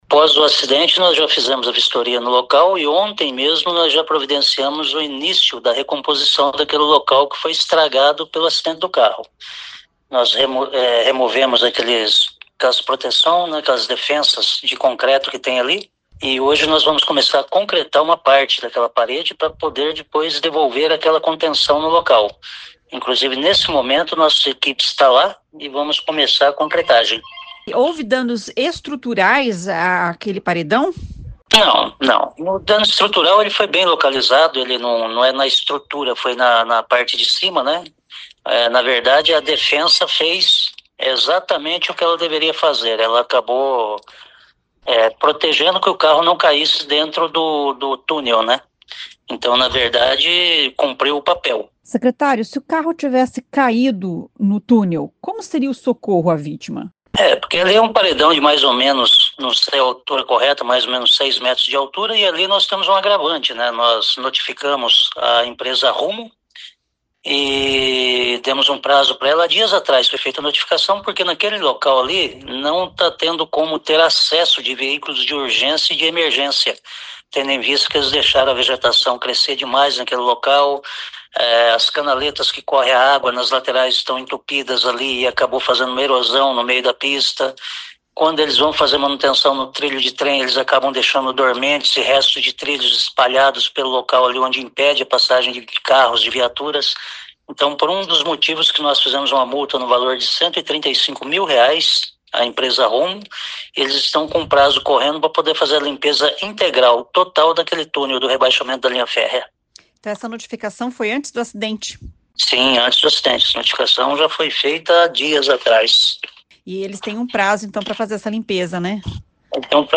O secretário de Infraestrutura, Vagner Mussio, diz que a mureta "cumpriu o papel" e agora precisa ser refeita, o que deve levar uma semana.